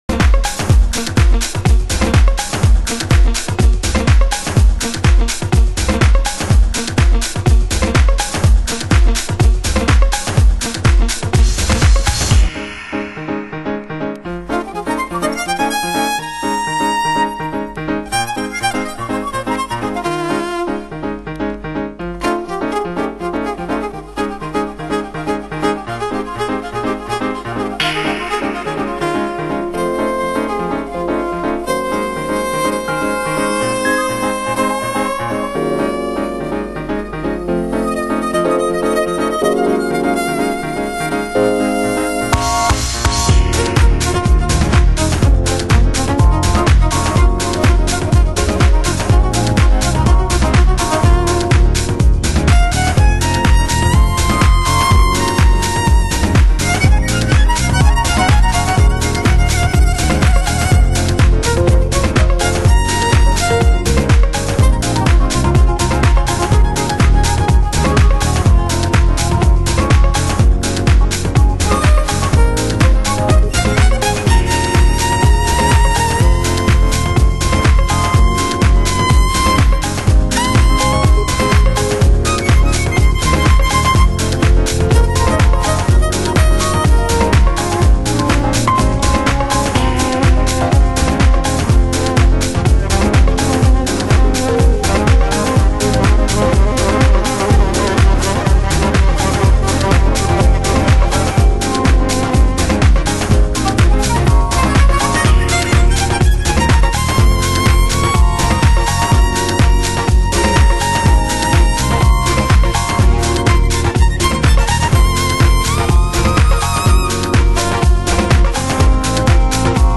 盤質：小傷有/少しチリパチノイズ有